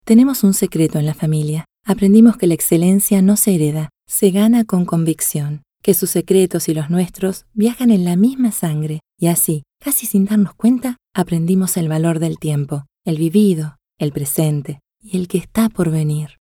Comercial